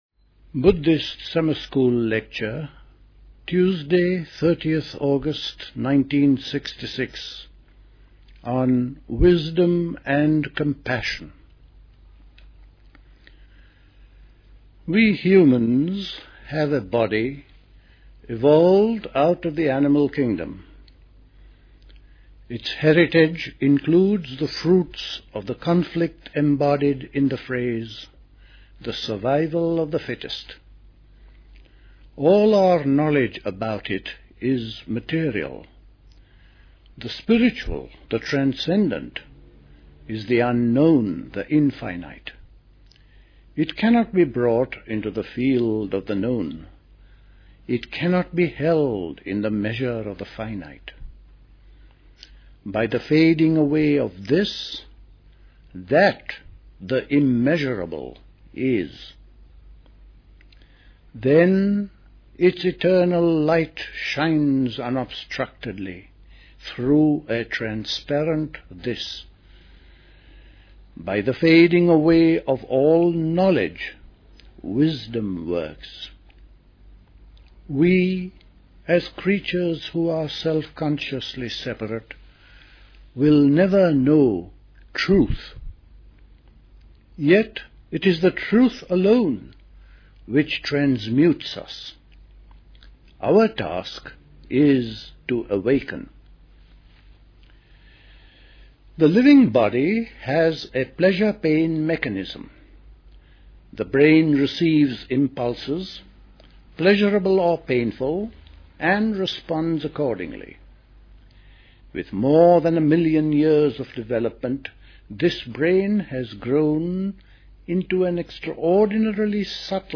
at High Leigh Conference Centre, Hoddesdon, Hertfordshire
The Buddhist Society Summer School